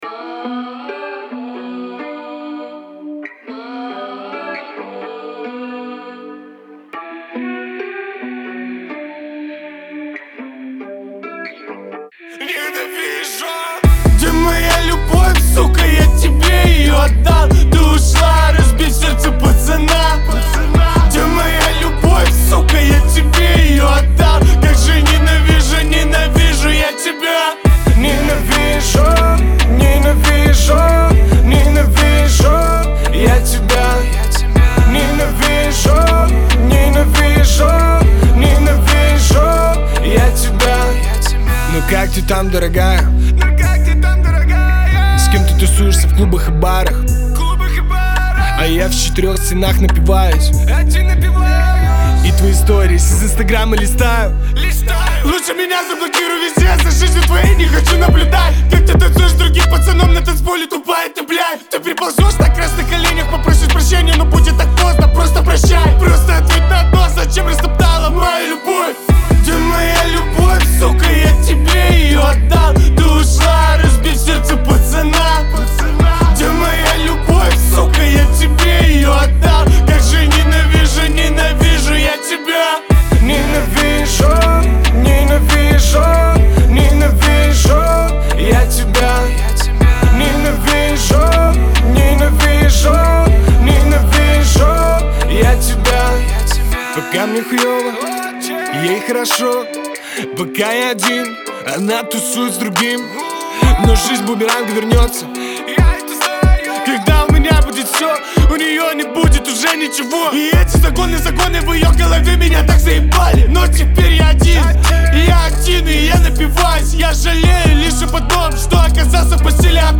это яркий пример современного поп-рока